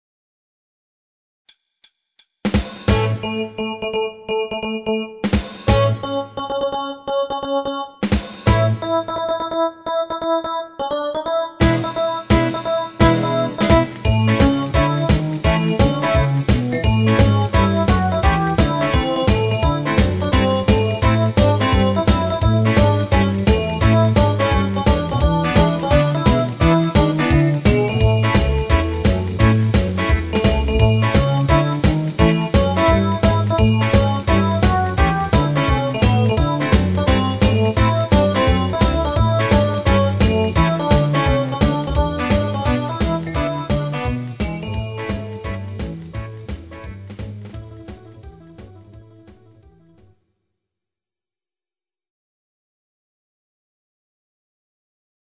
Genre(s): Internat.Pop  Oldies  |  Rhythmus-Style: Rocknroll